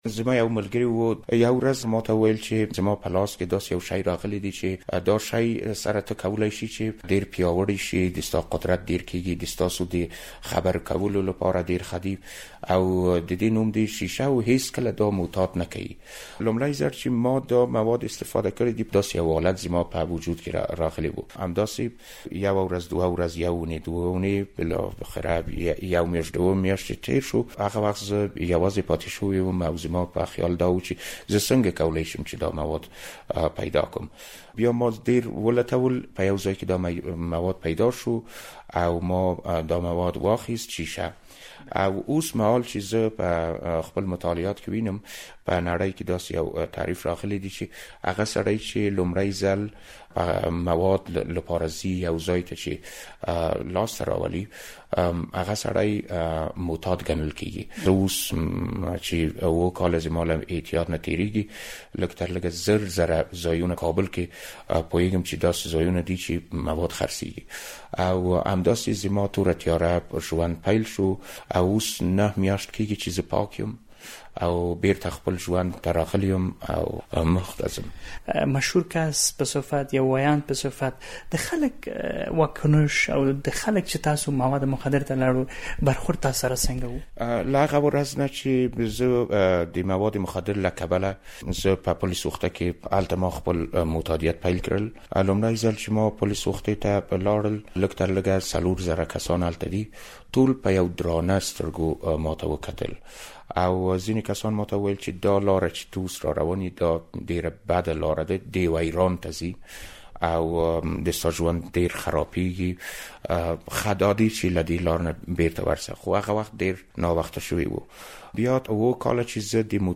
په دې اړه د ازادي راډیو خبریال ورسره مرکه کړې او په پيل کې يې پوښتلی چې ولې يې نشو ته مخه کړه؟